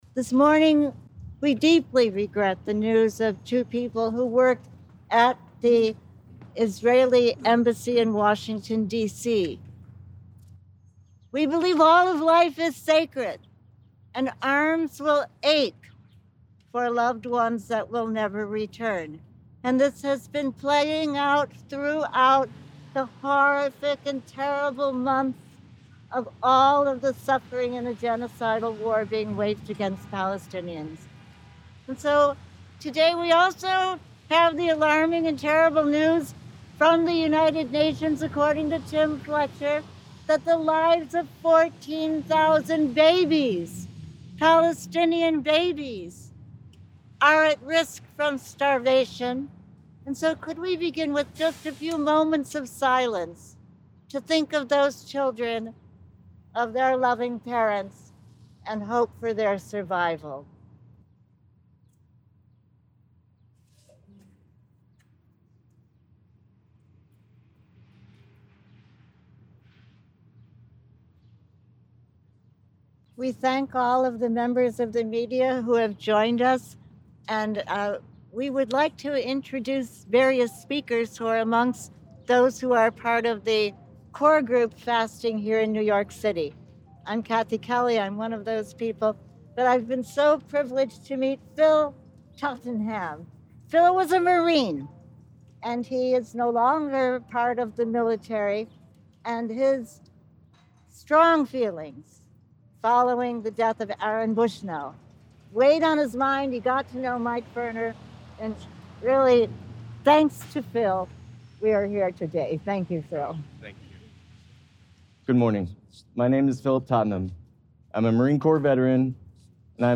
Location Recorded: Isaiah Wall, New York City